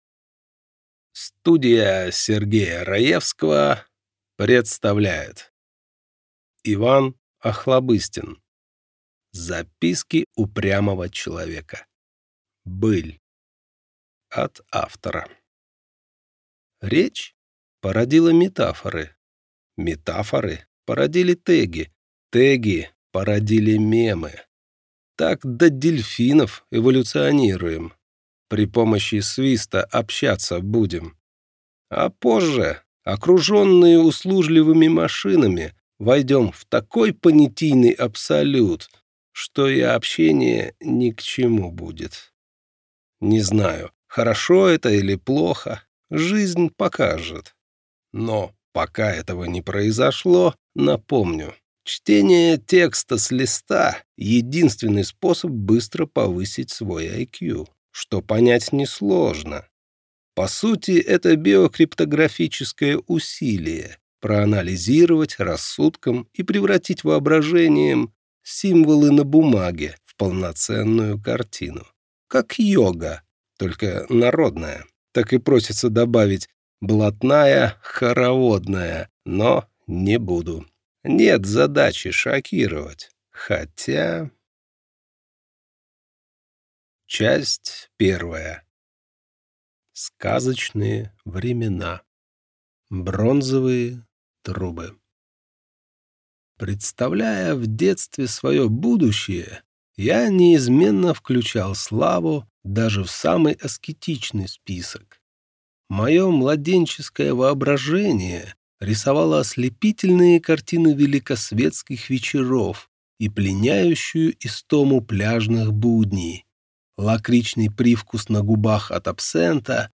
Аудиокнига Записки упрямого человека. Быль | Библиотека аудиокниг